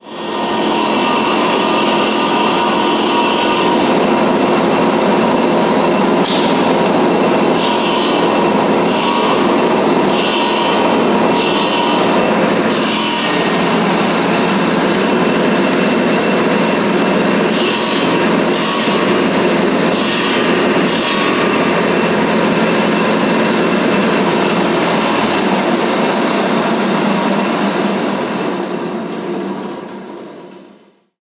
描述：Antropofonía：在纳里尼奥（帕斯托）的拿铁大学（Madeo）。 En el audio se percibe el sonido del motor de la sierra circular，ademásdelos sonidos del momento en el cual se corta la madera y esta entra en contacto con el disco decortemetálico。 2016年，当地时间为2016年，我们将为您提供最优惠的服务。 Anthropophany：在纳里尼奥大学（帕斯托）的木工车间录制。在音频中，除了切割木材并与切割盘金属接触的时刻的声音之外，还感知到圆锯的发动机声音。录音是用iPad拍摄的，并于2016年2月1日注册。